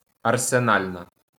Arsenalna (Ukrainian: Арсенальна, IPA: [ɐrseˈnɑlʲnɐ]